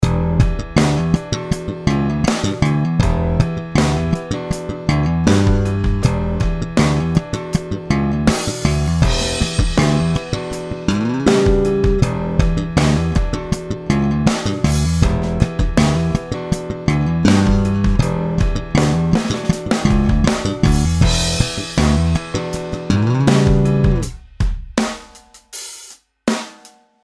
Jen tak pro porovnání jsem to samé nahrál na Fender JB 76 a Sandberg TM2, samozřejmě v pasivu a na singly:
Fender Jazz Bass 76
JB 76 je IMHO takový tenčí a sušší, Sandberg zase takový více pod dekou a méně hravý.